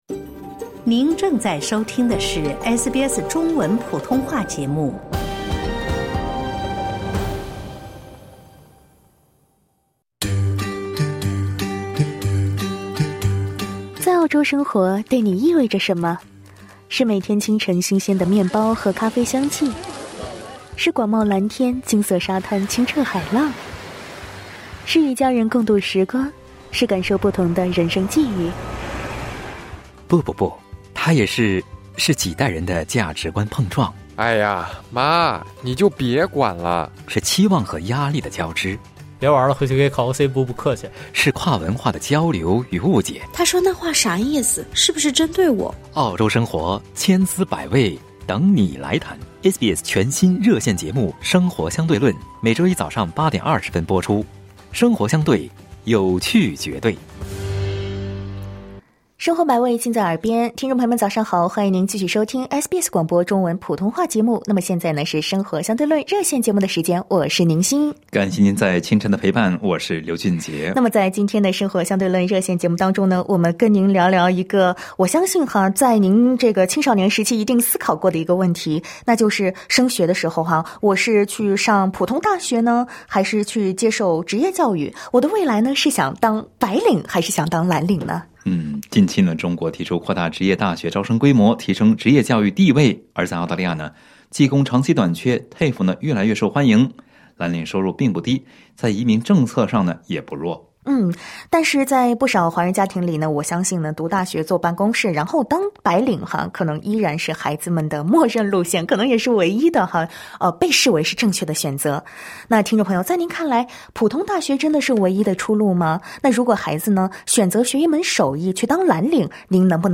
《生活相对论》热线节目 每周一早晨8:30在SBS普通话电台播出。